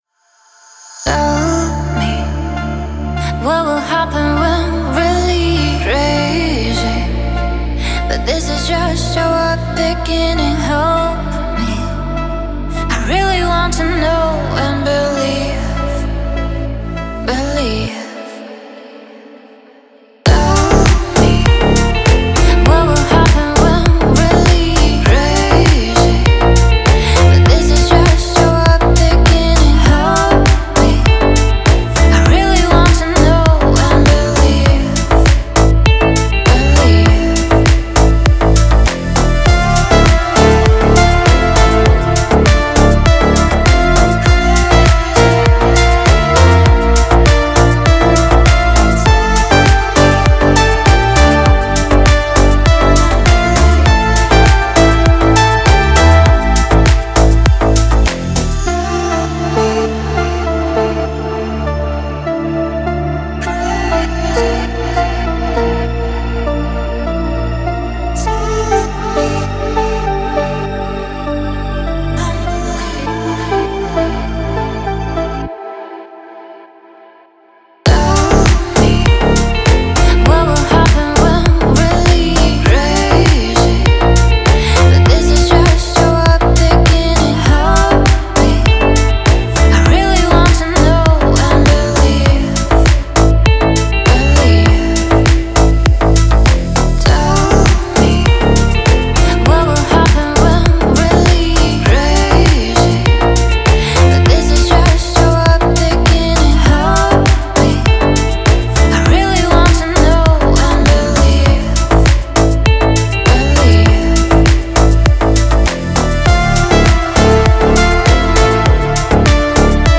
клубные песни